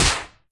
Media:RA_El_Tigre_atk_clean_004.wav 攻击音效 atk 局内攻击音效
RA_El_Tigre_atk_clean_004.wav